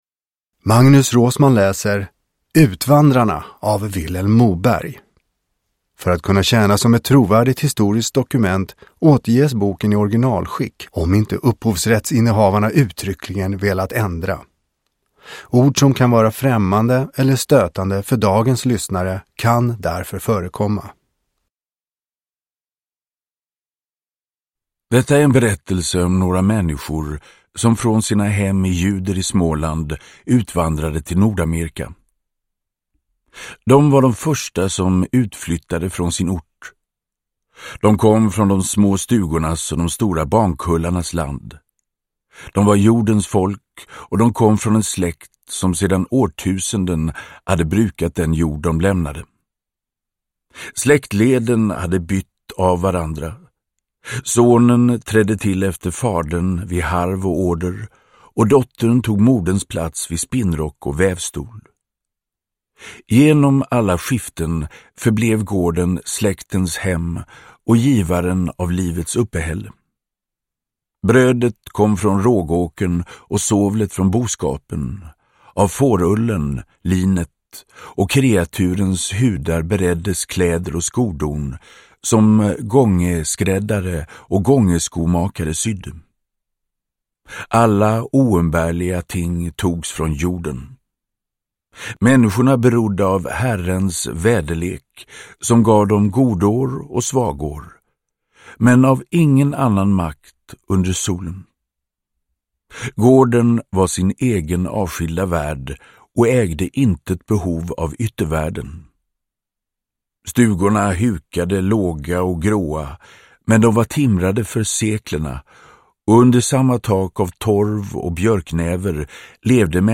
Utvandrarna – Ljudbok – Laddas ner
Uppläsare: Magnus Roosmann